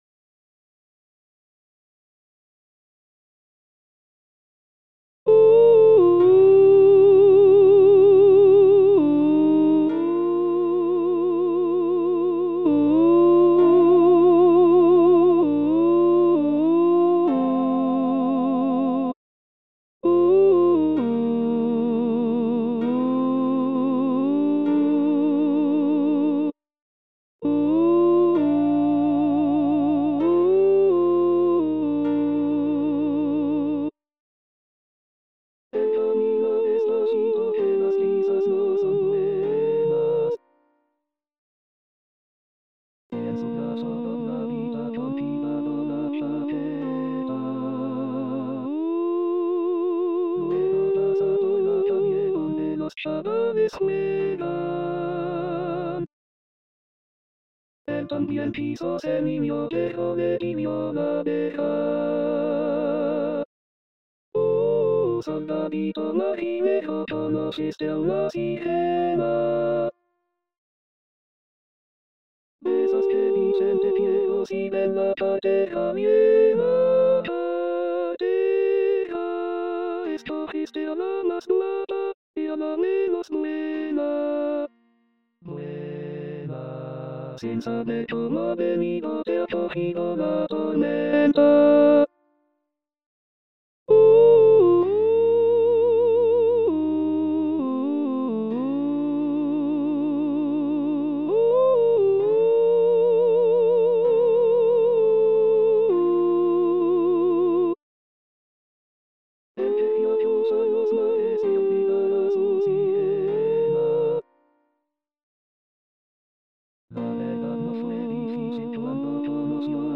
Contralto
2-Soldadito-Marinero-Contralto.mp3